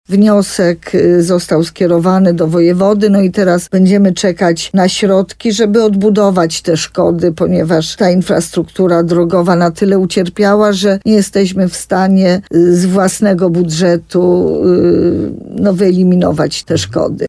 Jak powiedziała wójt gminy Sękowa Małgorzata Małuch, została już uruchomiona odpowiednia procedura, która ma zmierzać do zdobycia odszkodowań.